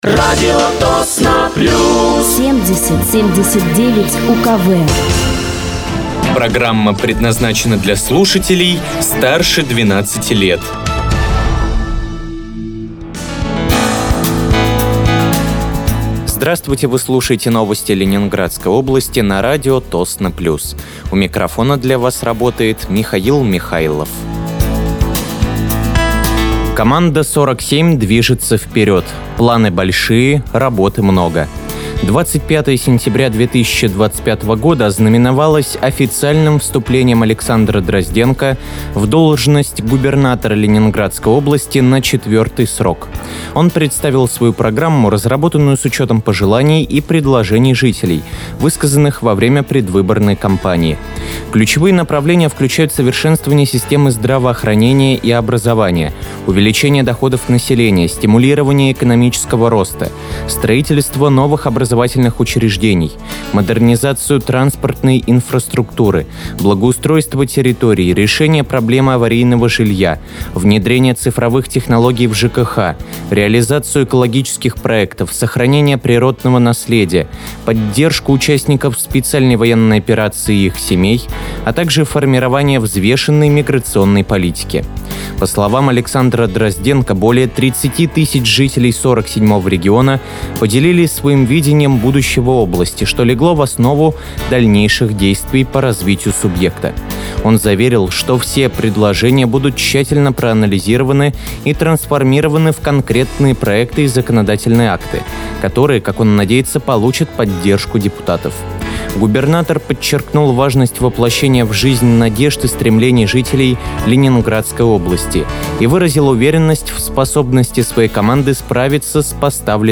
Вы слушаете новости Ленинградской области от 29.09.2025 на радиоканале «Радио Тосно плюс».